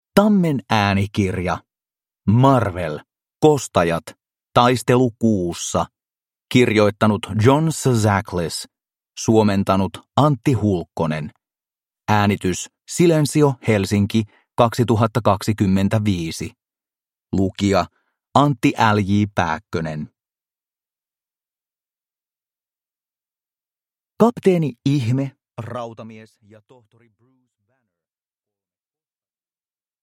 Marvel. Kostajat. Taistelu Kuussa – Ljudbok